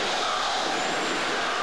CHANT8L.mp3